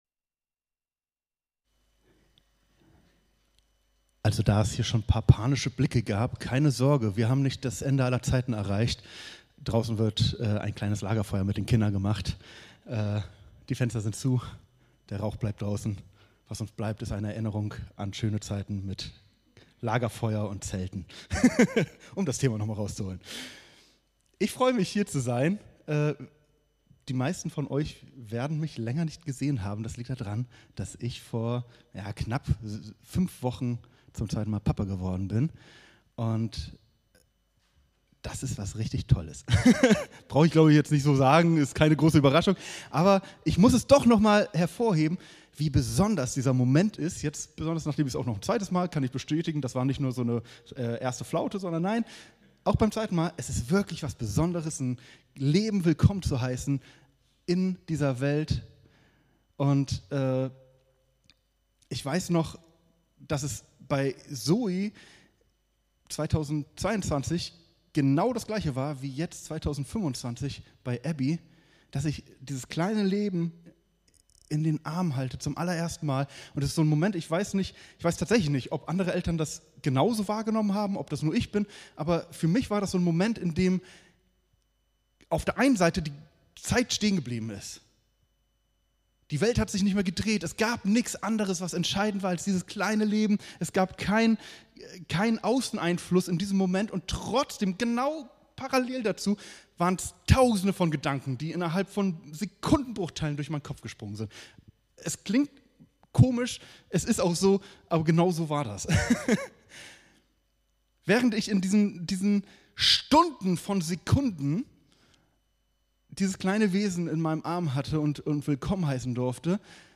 27.07.2025 - Taufe - mehr als Wasser ~ Predigten der Christus-Gemeinde | Audio-Podcast Podcast